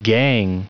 Prononciation du mot gang en anglais (fichier audio)
Prononciation du mot : gang